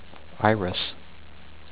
EYE-riss